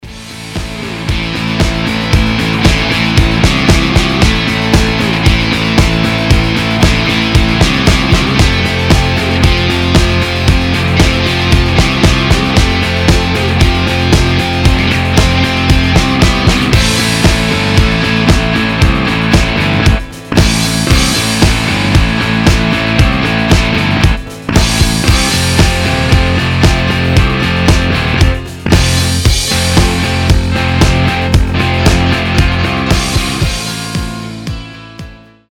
громкие
мощные
без слов
Alternative Rock
серьезные